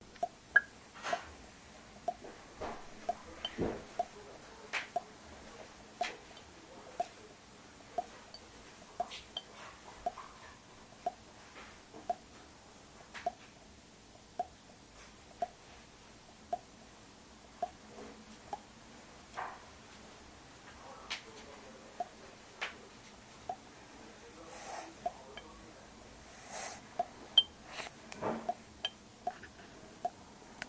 水滴在纸上 " 水在纸上02
描述：滴在纸上。
标签： 纸张
声道立体声